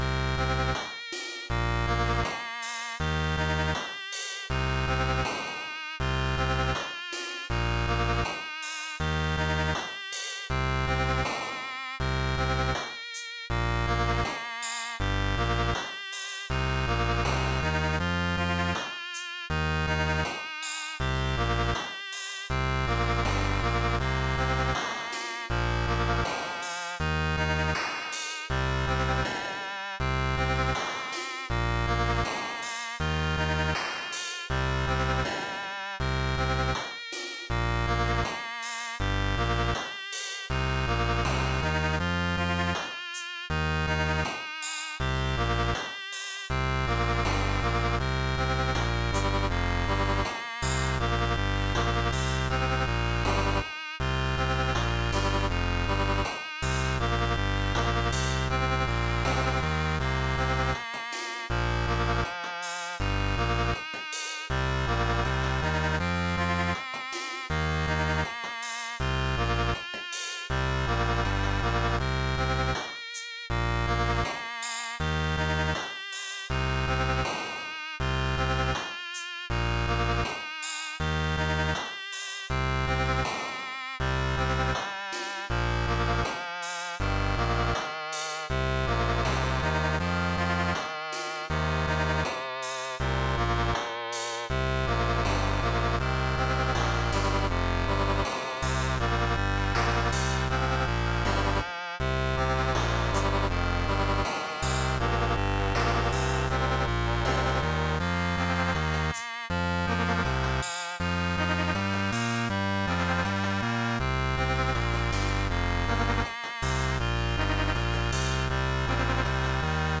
2 minute battle theme that loops